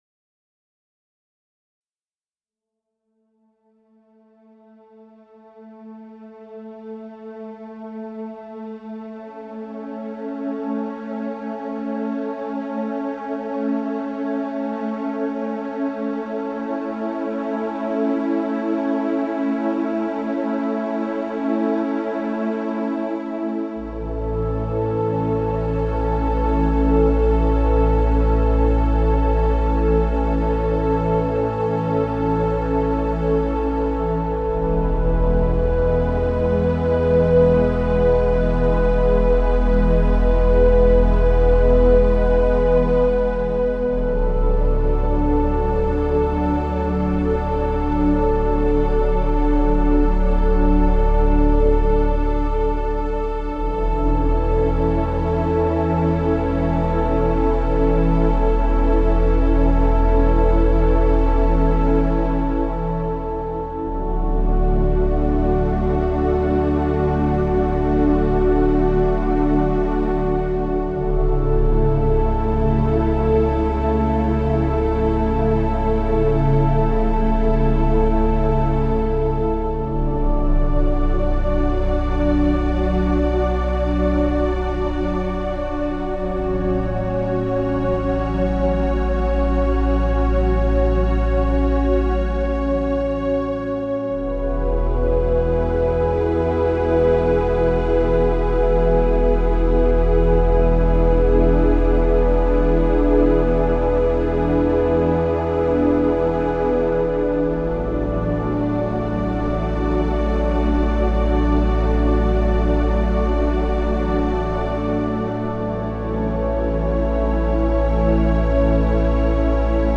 Calming music for stress relief, pre-sleep, and relaxation.